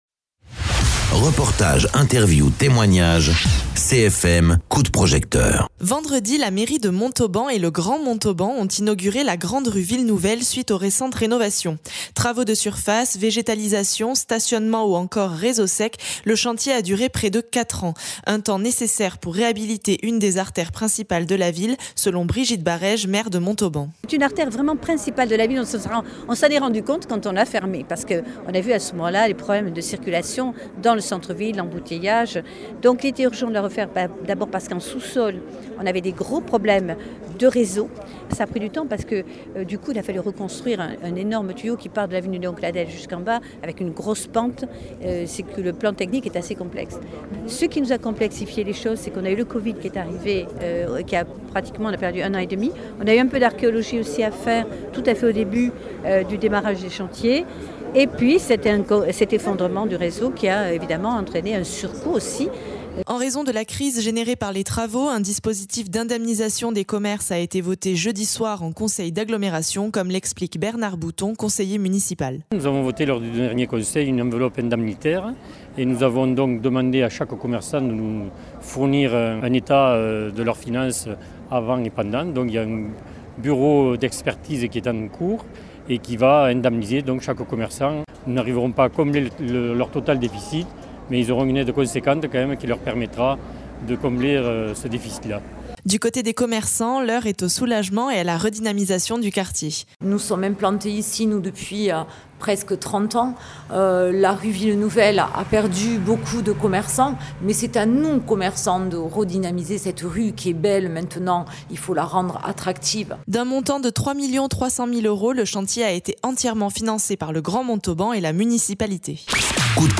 Interviews
Invité(s) : Brigitte Barèges, maire de Montauban
Bernard Bouton, conseiller municipal